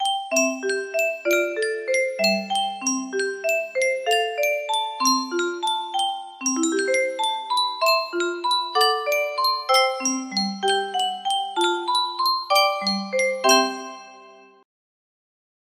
Yunsheng Music Box - When Irish Eyes Are Smiling Y218 music box melody
Full range 60